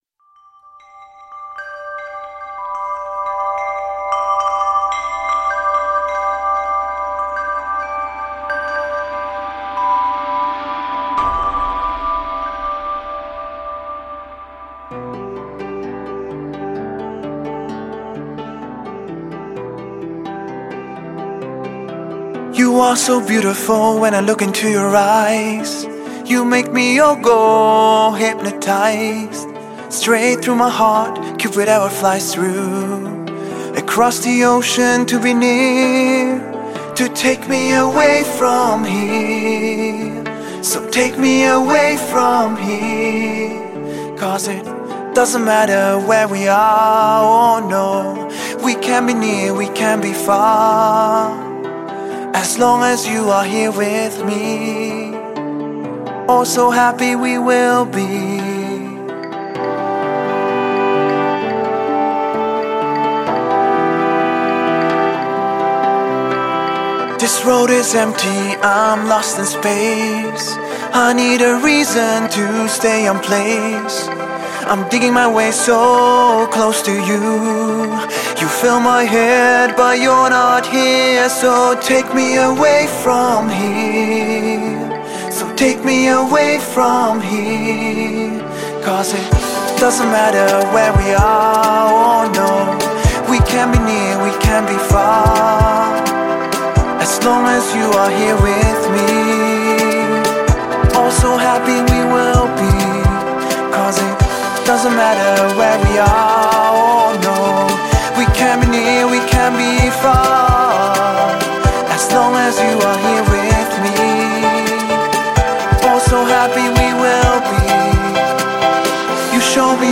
Žánr: Pop